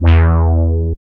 72.05 BASS.wav